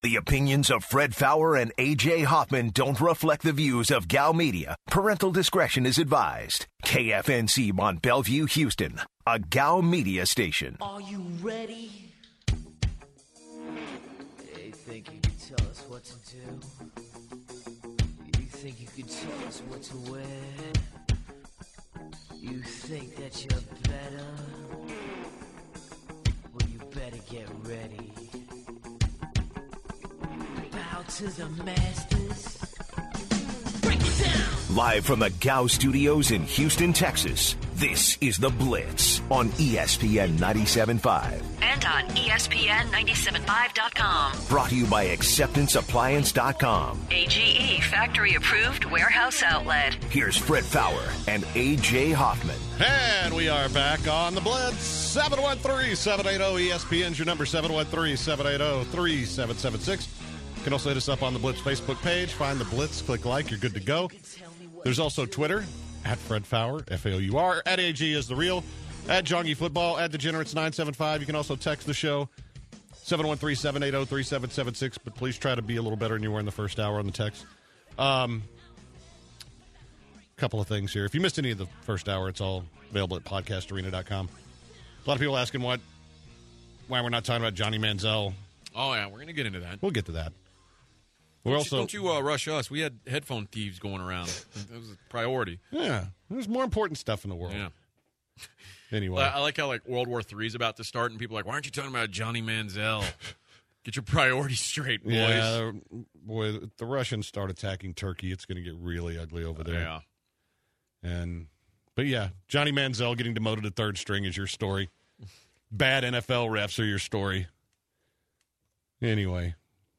To open the second hour, the guys discuss the NFL playoff picture. Plus, the guys take calls from fellow blitzers.